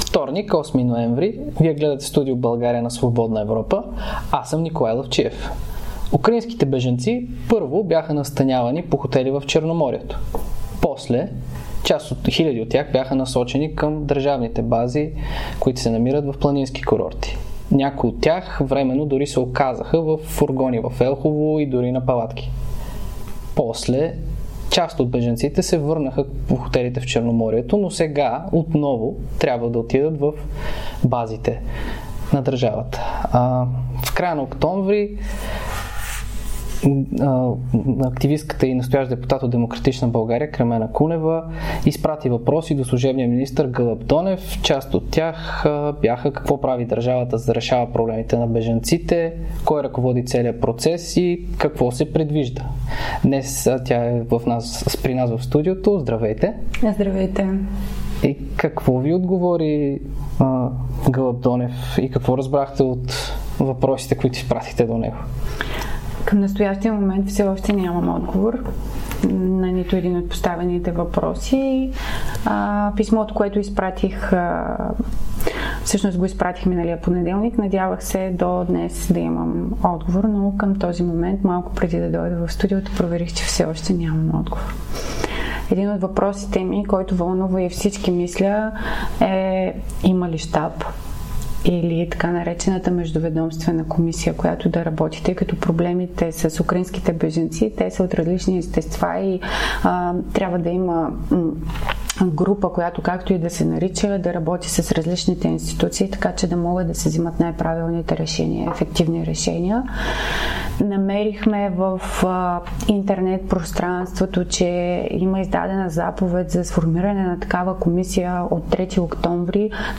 Това каза депутатът от "Демократична България" Кремена Кунева в интервю за Свободна Европа. По думите ѝ основният проблем за украинските бежанци в България е, че няма план за интеграция.